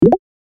ui-pop-up.mp3